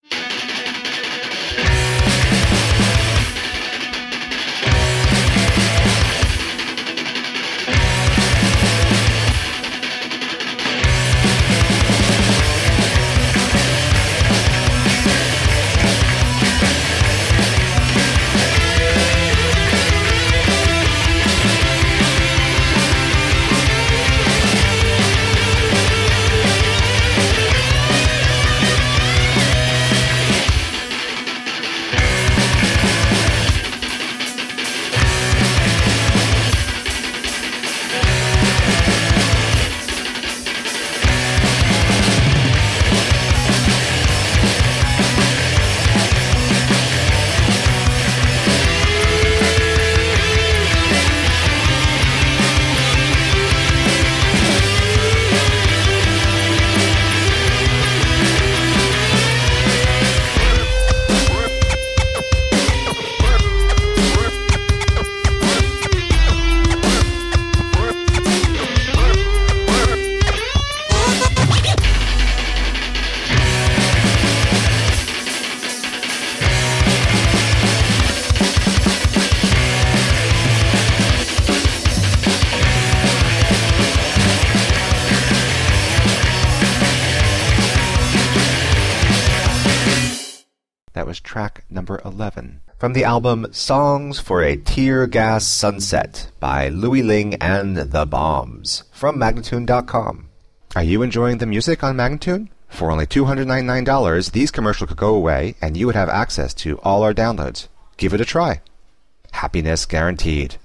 Punk rock to blow your head off.
Tagged as: Hard Rock, Punk, Intense Metal